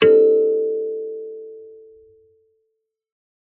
kalimba2_wood-A4-ff.wav